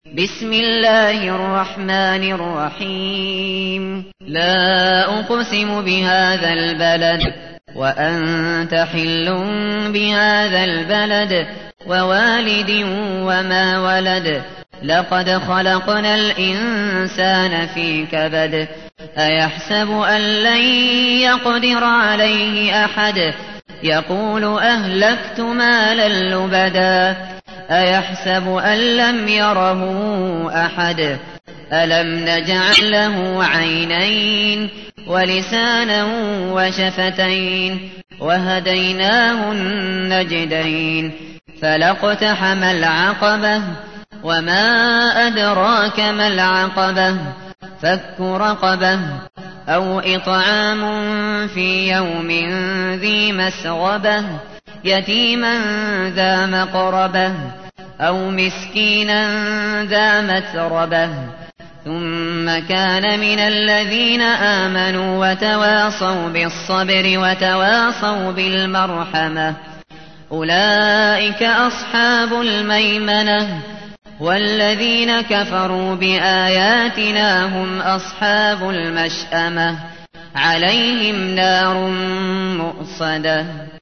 تحميل : 90. سورة البلد / القارئ الشاطري / القرآن الكريم / موقع يا حسين